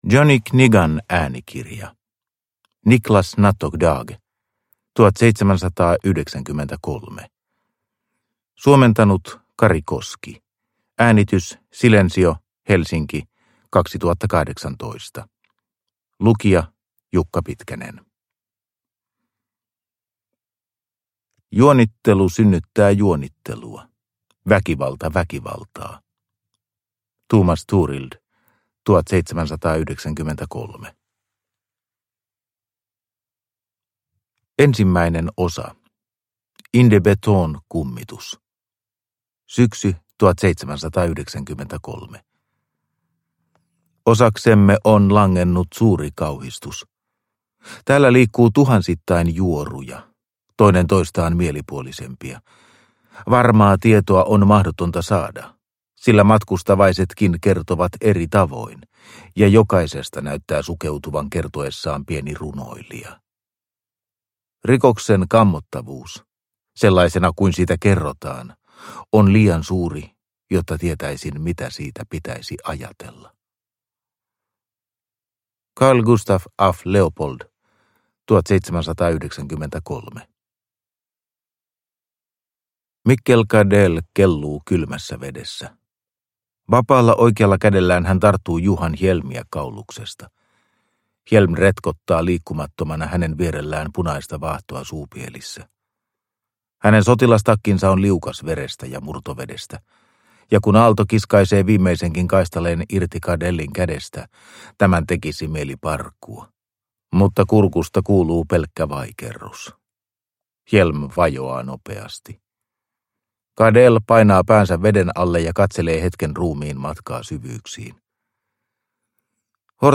1793 – Ljudbok – Laddas ner